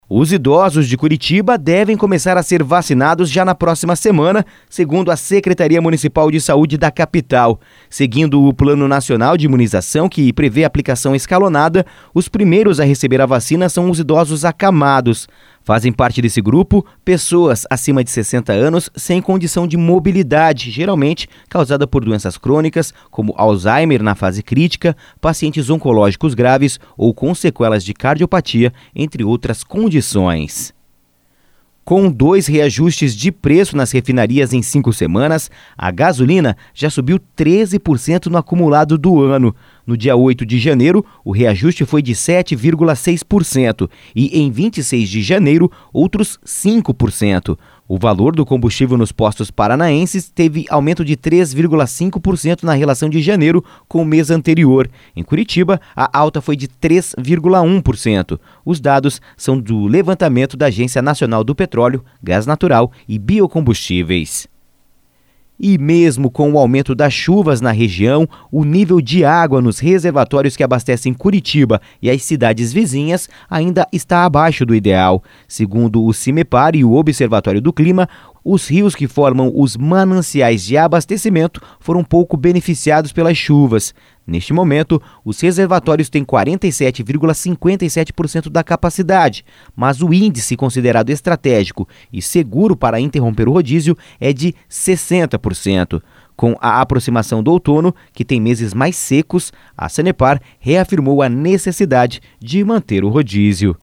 Giro de Notícias (SEM TRILHA)